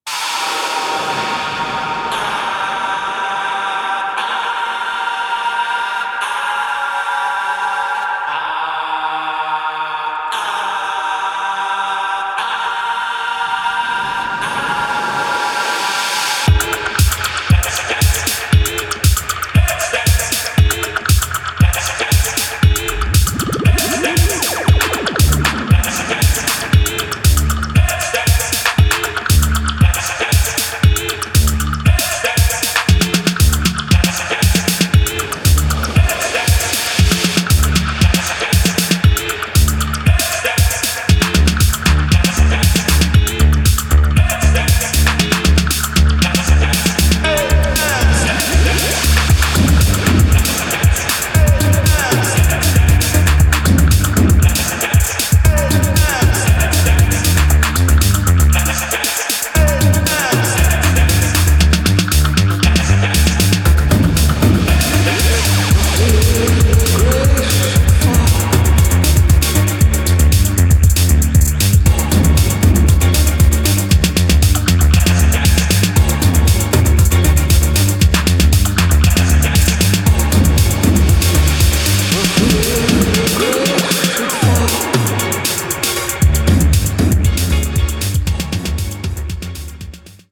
全編120BPMアンダー